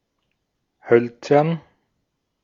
Ääntäminen
Vaihtoehtoiset kirjoitusmuodot (vanhentunut) wodden Synonyymit wood Ääntäminen US Tuntematon aksentti: IPA : /ˈwʊdən/ Haettu sana löytyi näillä lähdekielillä: englanti Käännös Konteksti Ääninäyte Adjektiivit 1.